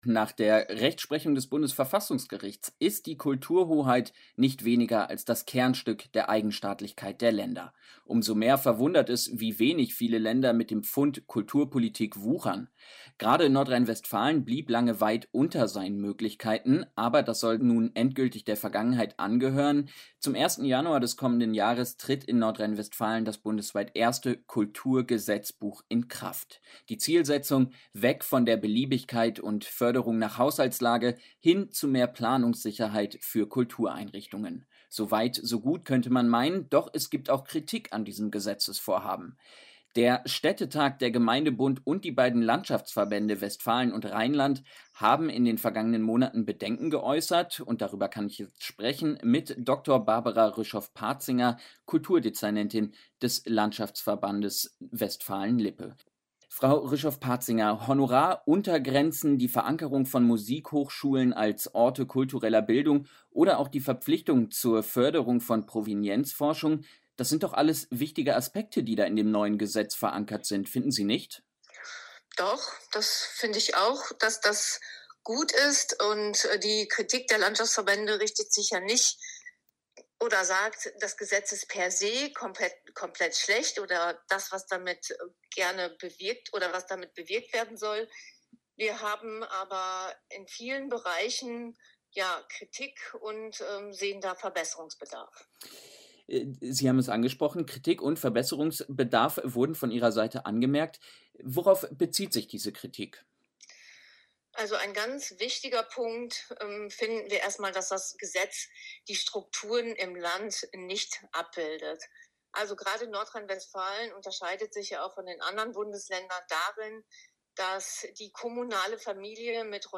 Ein Interview mit Barbara Rüschoff-Parzinger (Kulturdezernentin des Landschaftsverbandes Westfalen-Lippe)